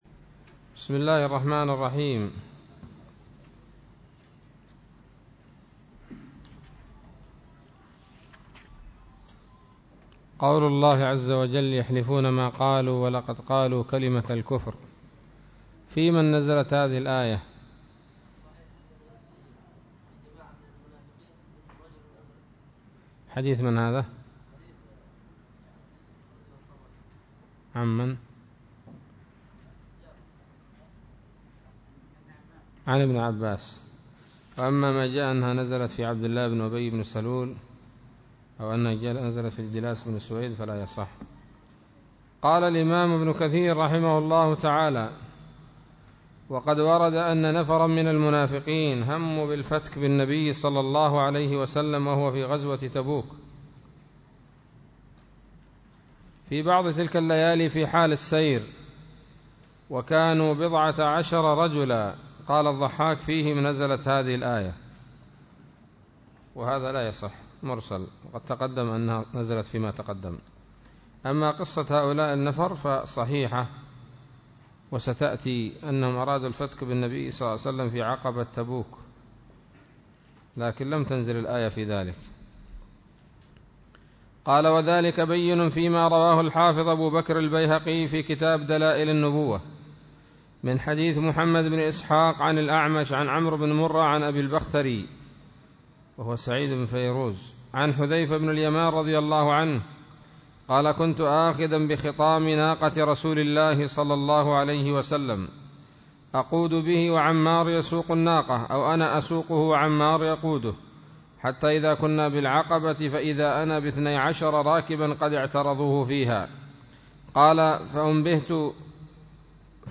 الدرس التاسع والعشرون من سورة التوبة من تفسير ابن كثير رحمه الله تعالى